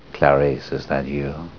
There are three messages from Anthony Hopkins (doing Dr. Lecter) that you can download. One is for a Welcome, when you log on